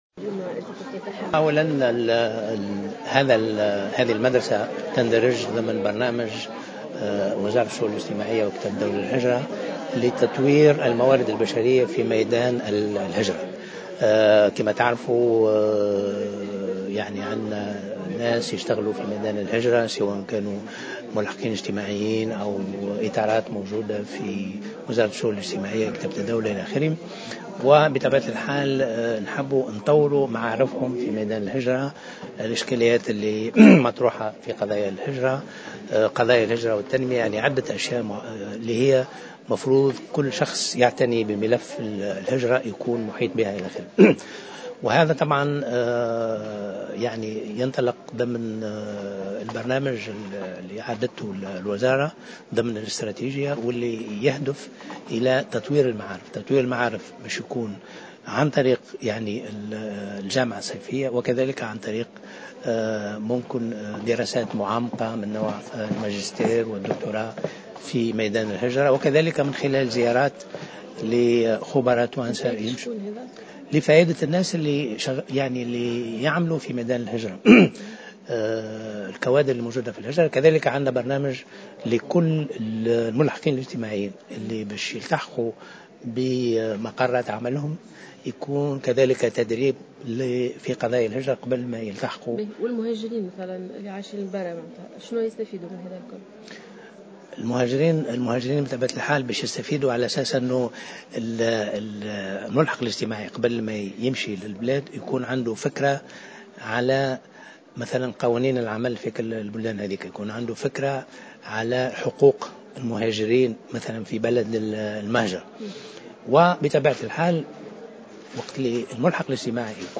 أكد كاتب الدولة للهجرة بلقاسم الصابري في تصريح لمراسل الجوهرة "اف ام" صباح اليوم الإثنين 14 سبتمبر 2015 على هامش افتتاح أول مدرسة صيفية حول الهجرة في المجتمع التونسي أن السلطات التونسية تعامل اللاجئين السوريين معاملة التونسيين ولافرق بينهم وبين أي تونسي من حيث التمتع بالحقوق كالصحة والتعليم وفق قوله.